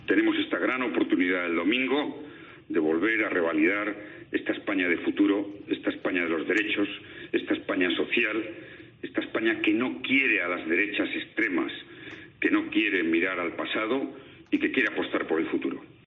La llamada electoral de Zapatero a COPE
"Hola, soy José Luis, Zapatero. Te pido que el domingo vayamos a votar", comienza así el mensaje grabado que están recibiendo los ciudadanos, a los que reclama su apoyo "en favor de la democracia, de la igualdad de género, de los derechos sociales...".